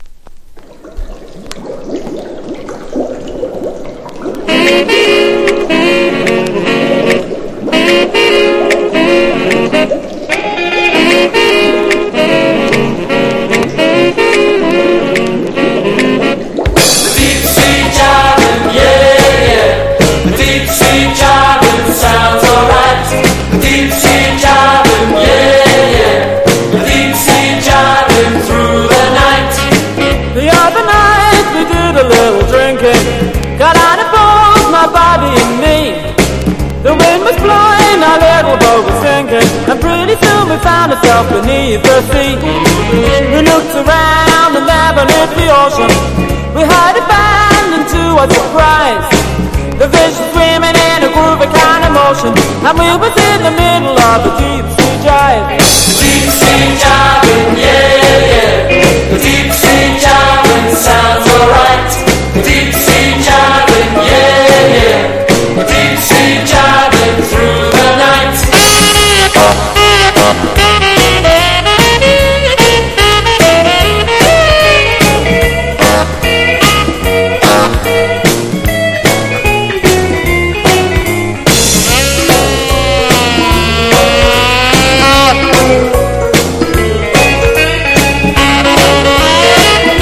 80'Sジャンプ&ジャイブ、ボッサやR&B、ラテン等パーティーチューンだらけの人気盤！
ROCKABILLY / SWING / JIVE